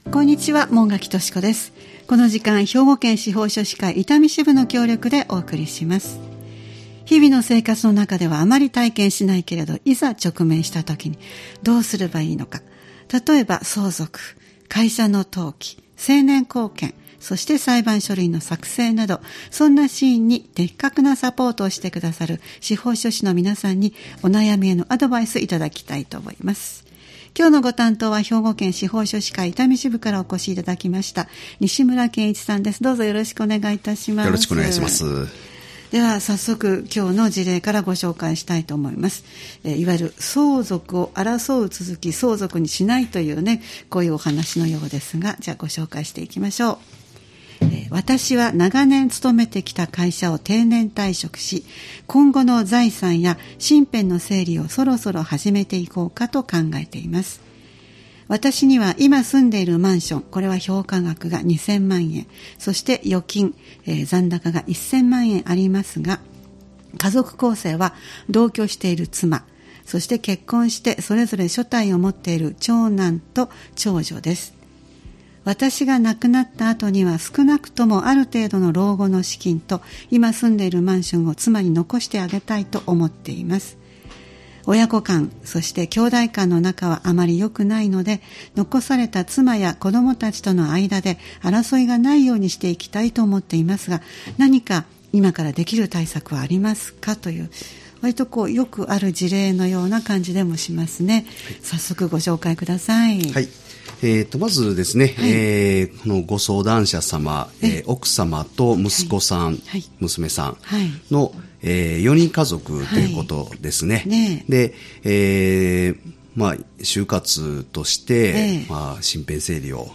毎回スタジオに司法書士の方をお迎えして、相続・登記・成年後見・裁判書類の作成などのアドバイスを頂いています。